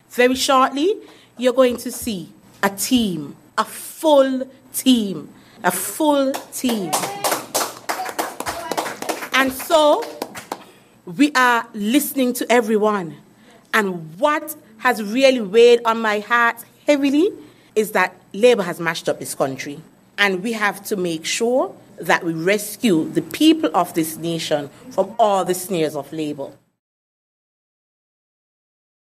Leader of PAM, Mrs. Natasha Grey-Brookes.